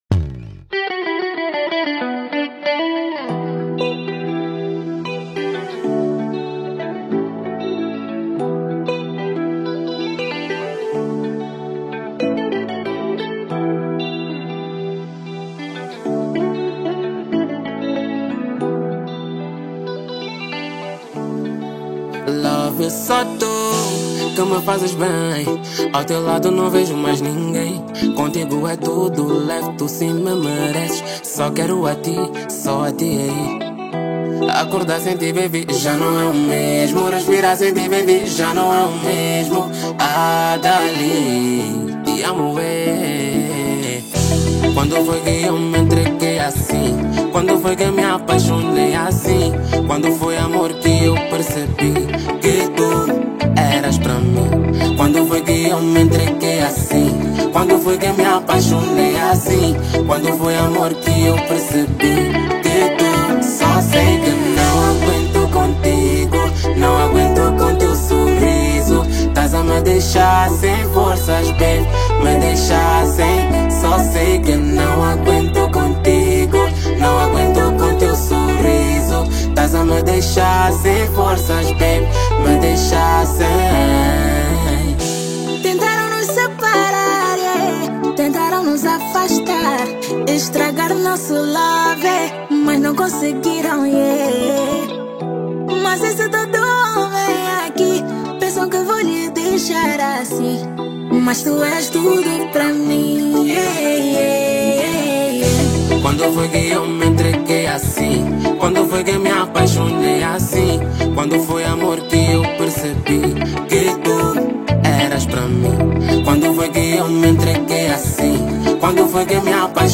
Genero: Pop